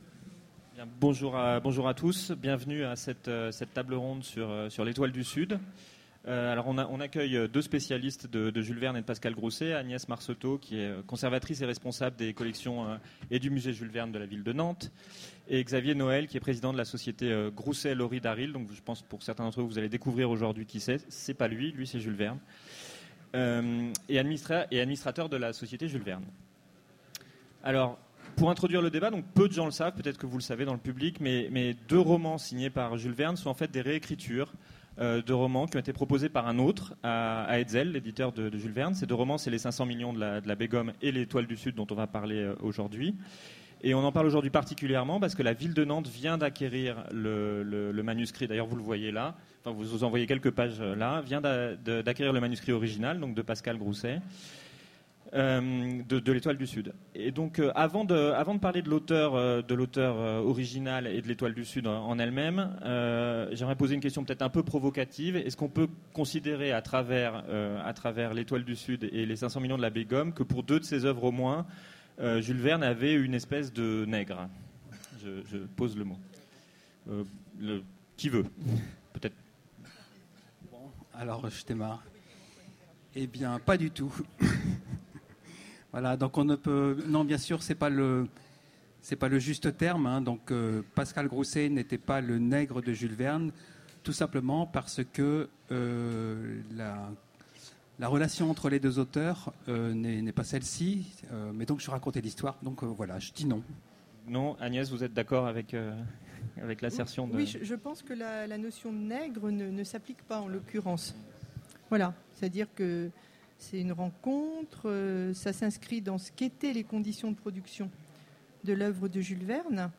Mots-clés Jules Verne Conférence Partager cet article